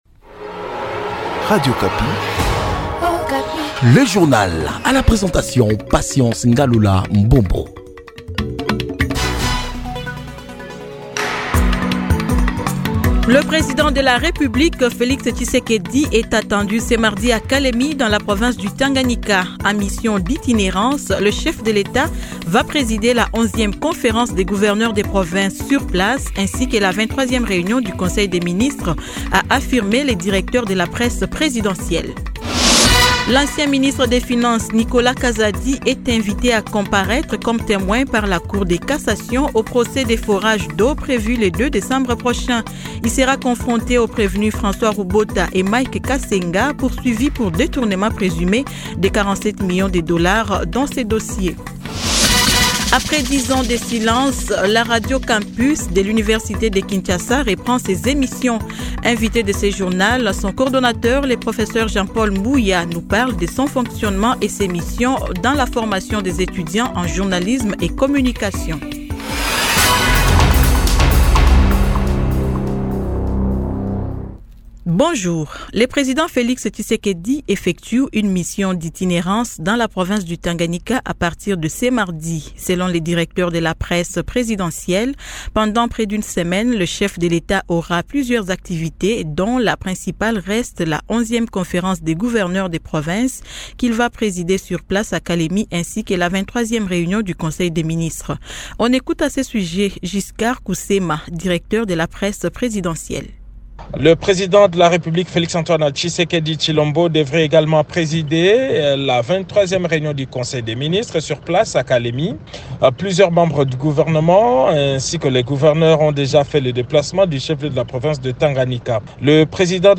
Journal matin 06H-07H